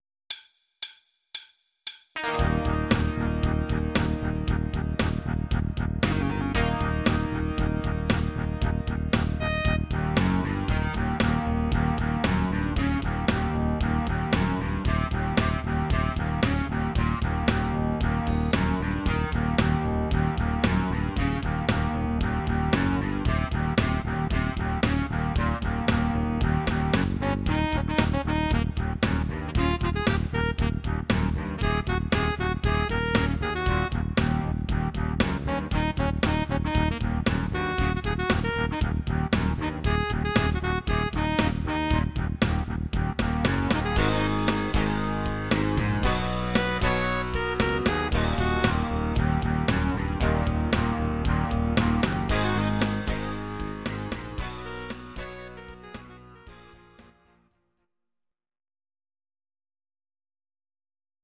These are MP3 versions of our MIDI file catalogue.
Please note: no vocals and no karaoke included.
Your-Mix: Rock (2958)